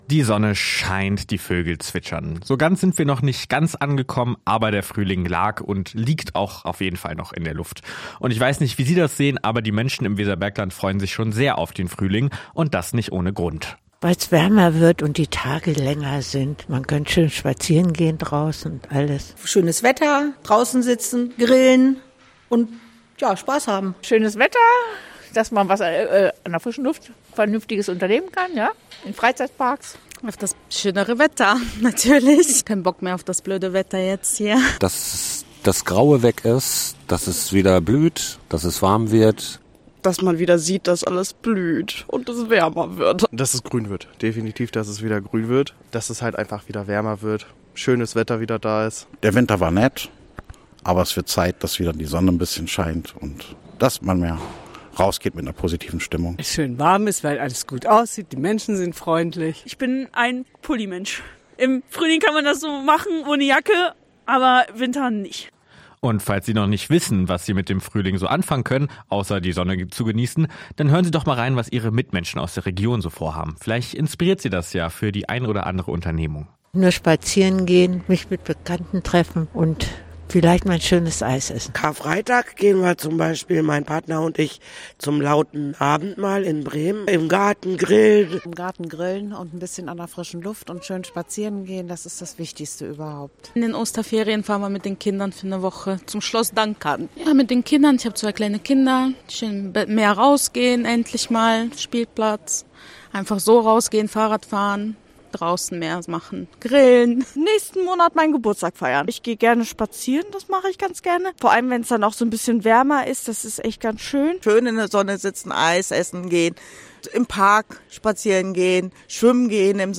Umfrage: Frühling im Weserbergland – radio aktiv
umfrage-fruehling-im-weserbergland.mp3